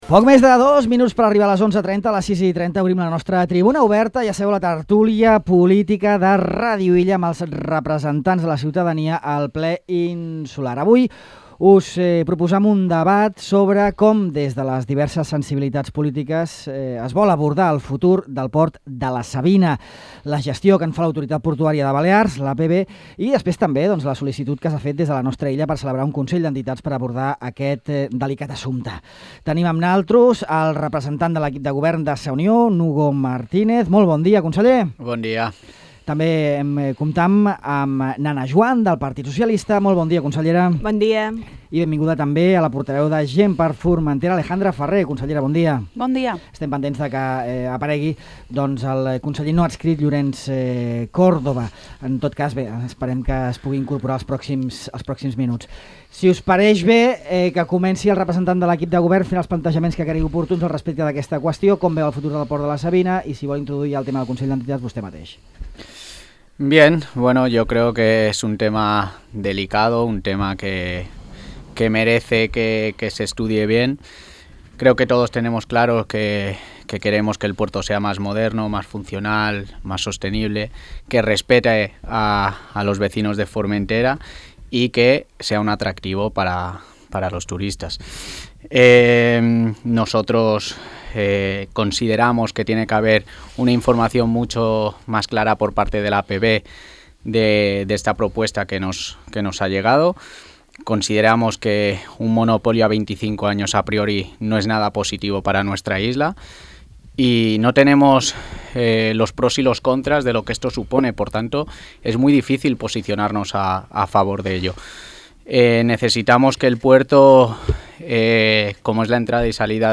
Hugo Martínez, conseller i portaveu de l’equip de Govern de Sa Unió; Alejandra Ferrer, portaveu de Gent per Formentera; Ana Juan, consellera del PSOE; i Llorenç Córdoba, conseller no adscrit, debaten sobre el futur del port de la Savina i la licitació dels amarraments esportius i espais diversos que està impulsant l’Autoritat Portuària de Balears (APB).